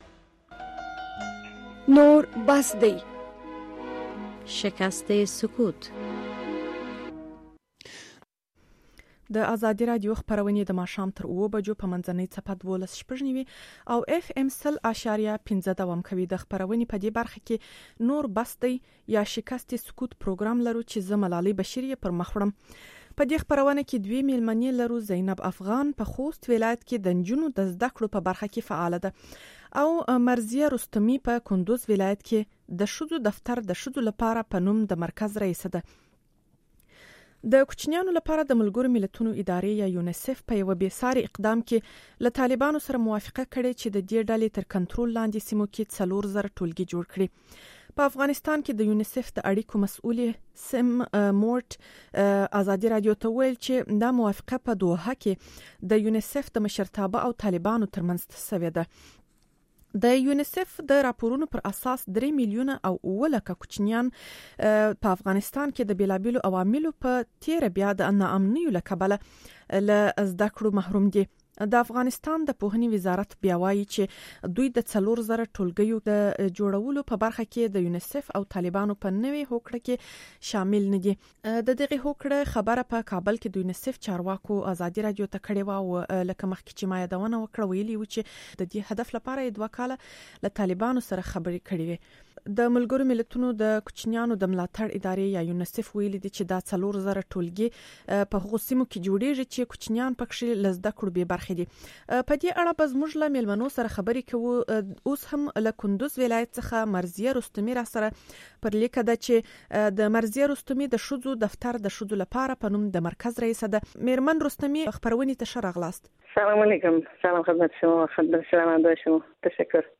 د ازادي راډیو د مخکښې ښځې پروګرام په دې برخه کې دوې مېلمنې لرو.